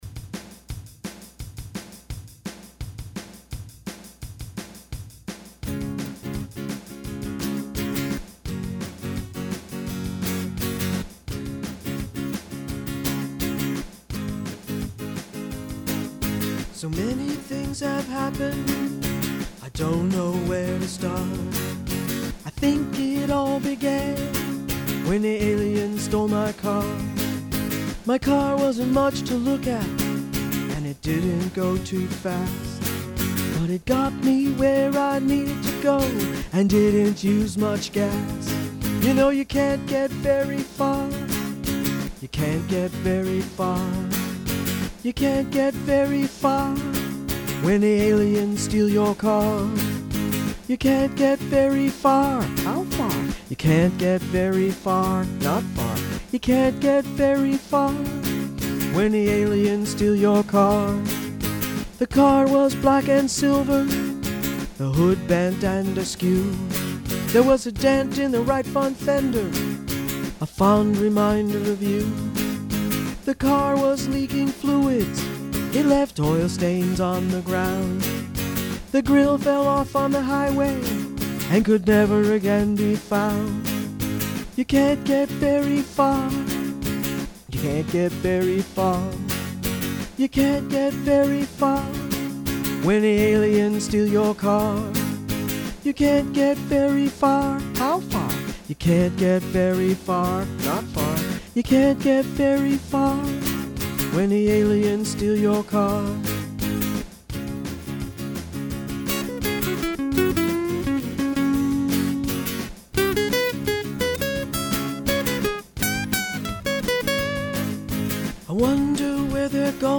Politically Incorrect Modern Blues